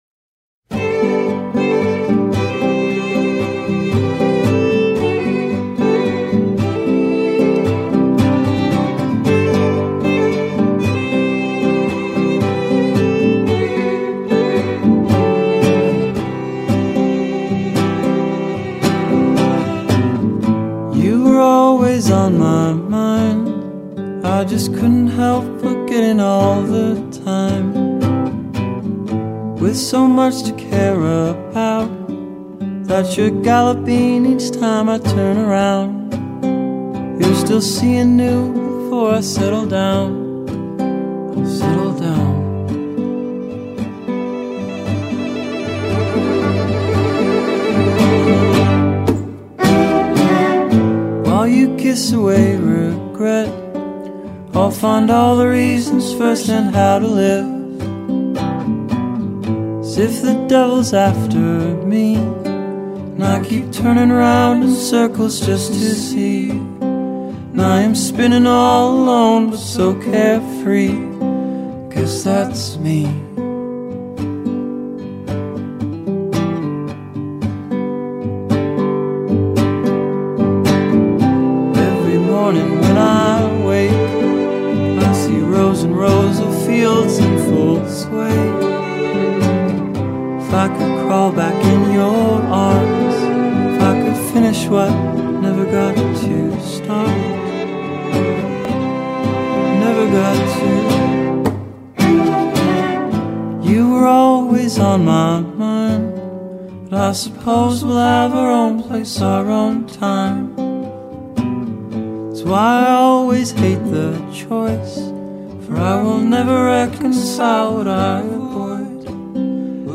singer-songwriter
indie three-piece
poignant and wistful violin laced track